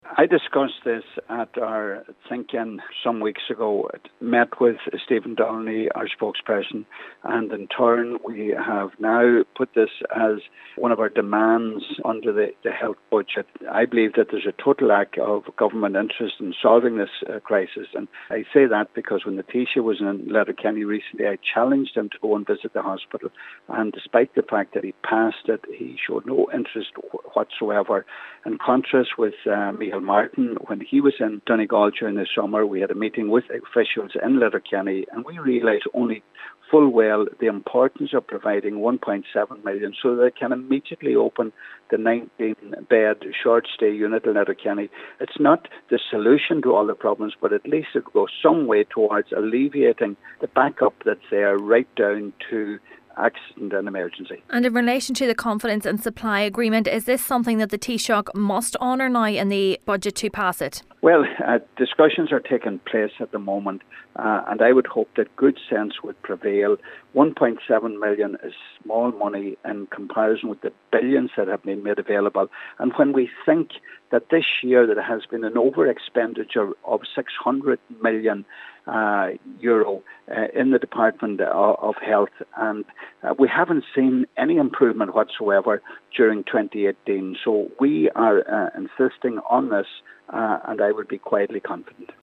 Lease Cheann Comhairle and Donegal Deputy Pat the Cope Gallagher says this is the best possible opportunity to solving the ongoing overcrowding crisis at the hospital and he is confident the Taoiseach will include the funding in the upcoming budget………….